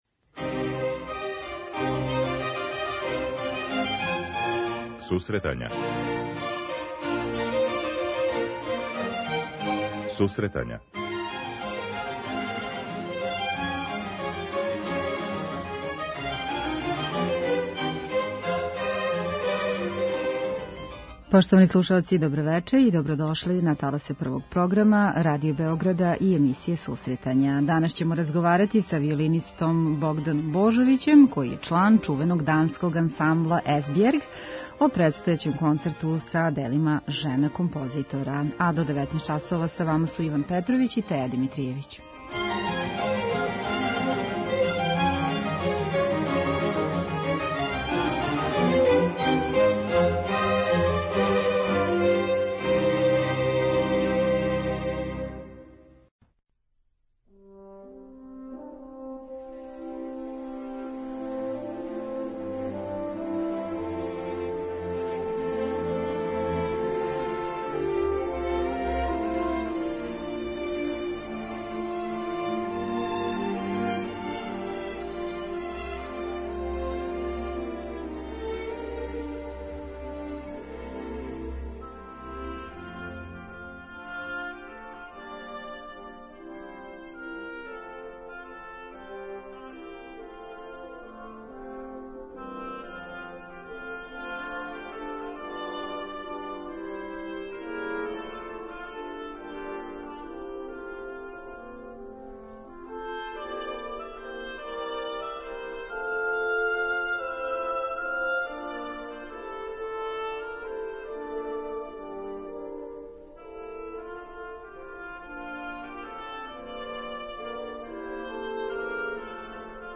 Данас разговарамо са виолинистом